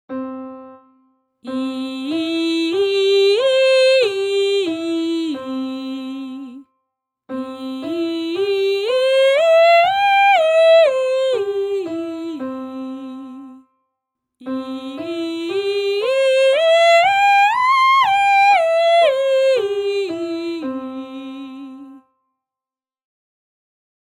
Stemtesten Bereik
met Stemtest 2 Vrouw
Stemtest-vrouw-2.mp3